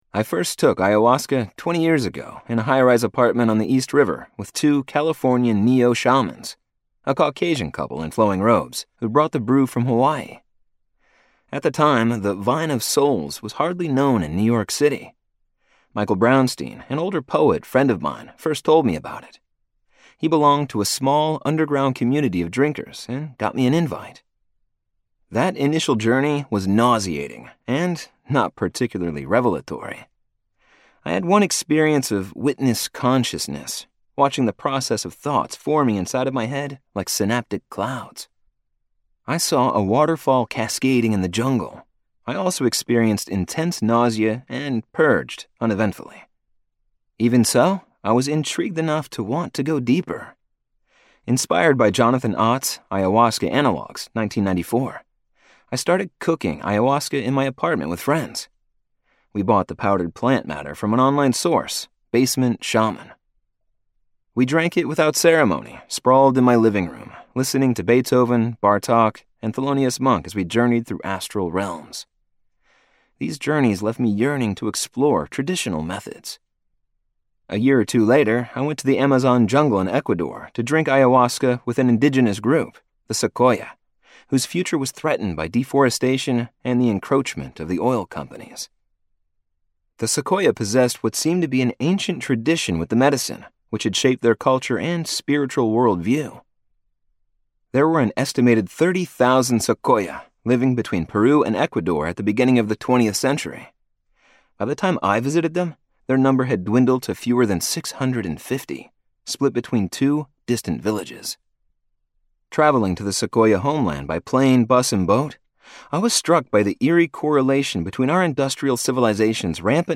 When Plants Dream - Vibrance Press Audiobooks - Vibrance Press Audiobooks